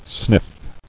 [sniff]